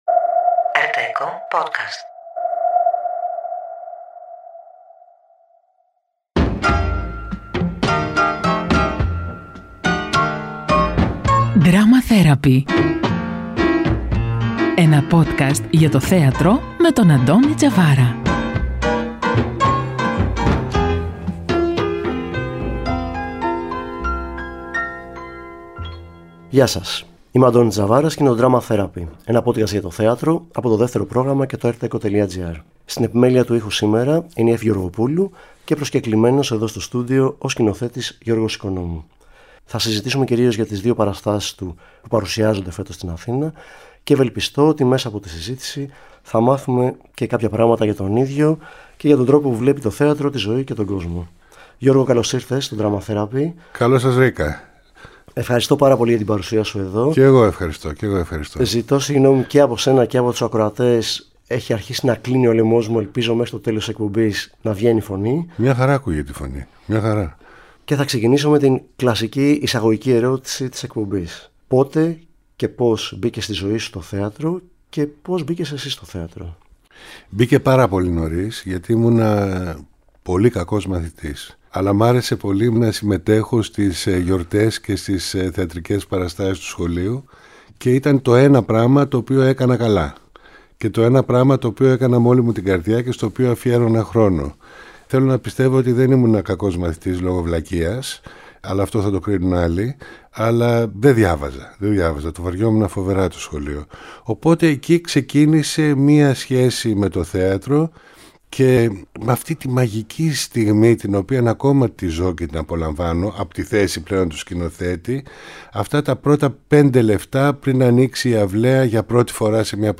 Ένα podcast για το θέατρο από το Δεύτερο Πρόγραμμα και το ERTecho Στο Drama Therapy άνθρωποι του θεάτρου, κριτικοί και θεατές συζητούν για τις παραστάσεις της σεζόν αλλά και για οτιδήποτε μπορεί να έχει ως αφετηρία ή ως προορισμό τη θεατρική πράξη.